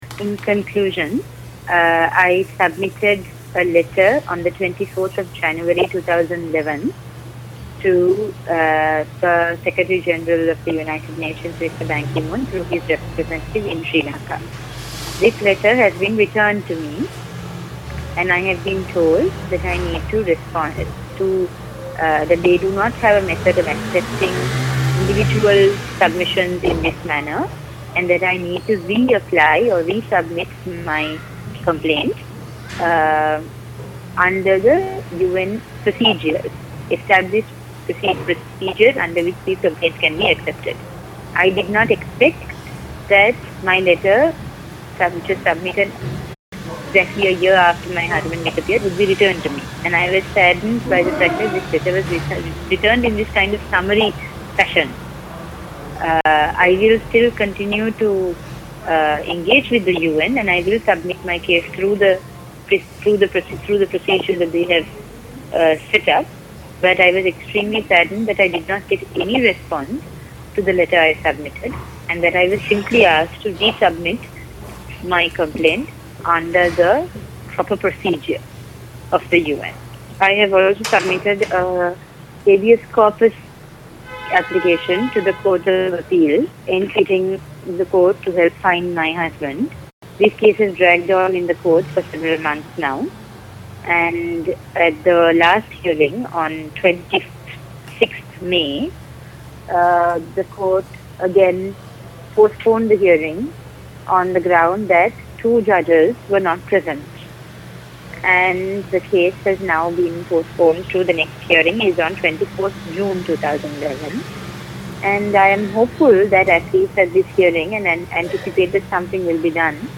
Sri Lanka - Interview de (...) 12.